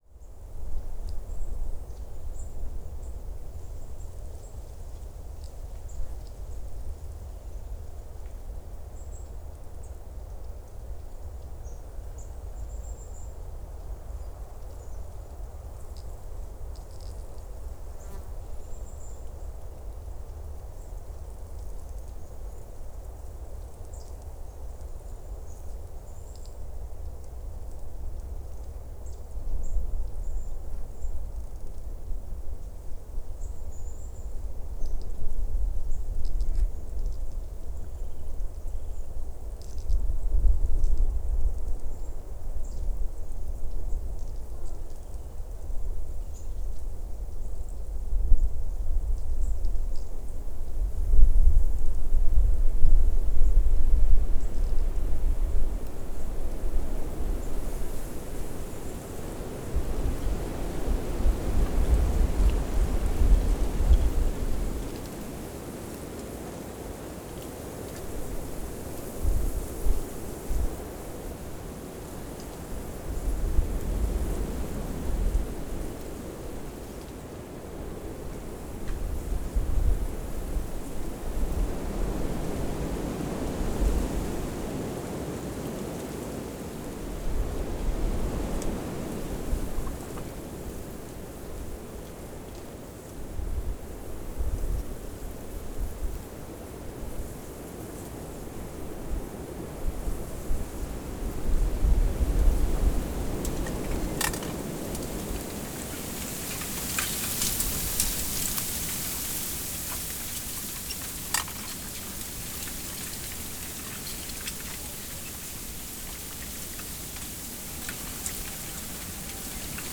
Wind
Diferentes aires en movimiento mezclados en este proyecto llamado «Wind» y grabado en Riumors, Cerdanyola y Sant Cugat del Vallés en Catalunya.
[ENG] Different winds mixed in this project called «Wind» and recorded in Riumors, Cerdanyola and Sant Cugat del Valles in Catalonia.
master-vent1.wav